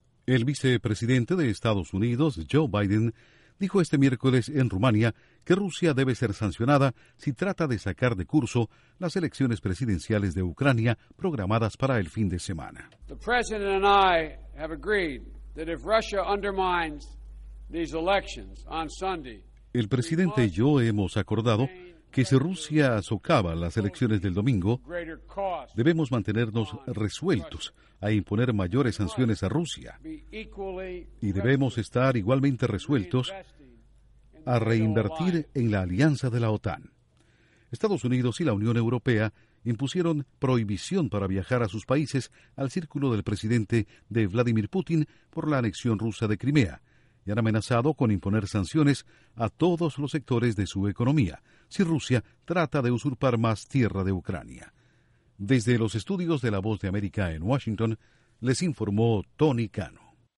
El vicepresidente de Estados Unidos, Joe Biden, advirtió a Rusia que no se inmiscuya en las elecciones del domingo en Ucrania, porque podría enfrentar serias consecuencias. Informa desde la Voz de América en Washington